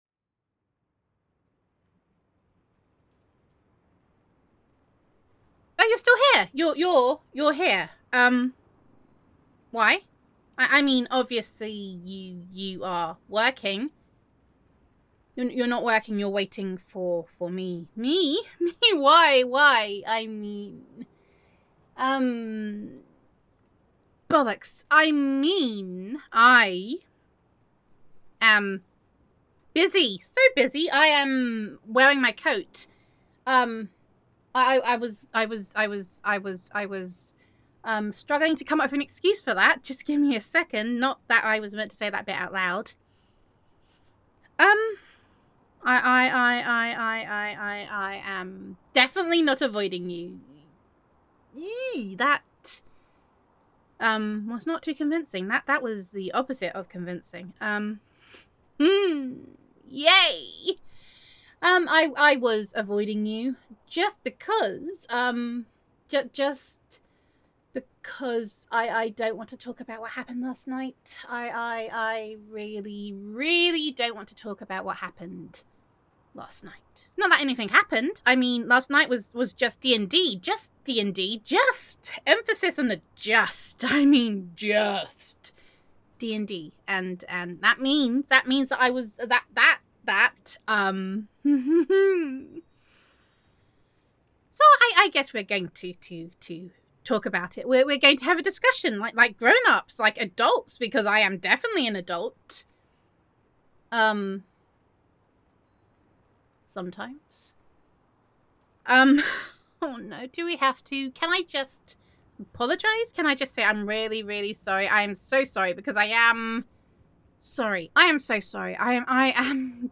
[F4A]
[Co-worker Roleplay]